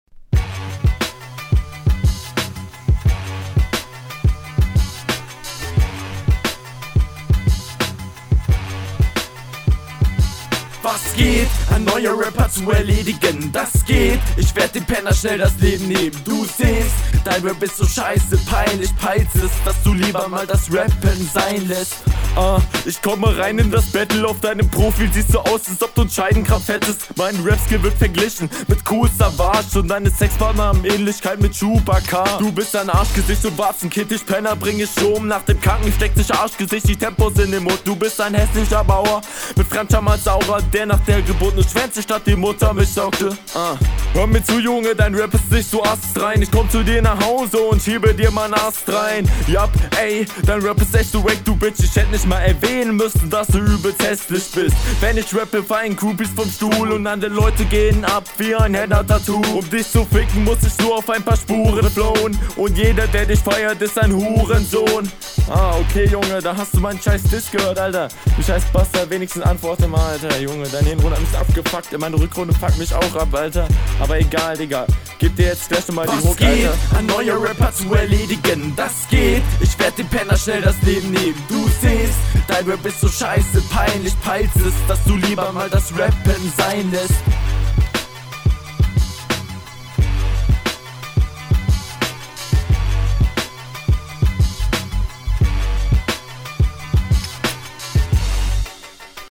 War ganz gut, bei der mitte fand ich den Cut allerdings etwas stark hörbar, schade …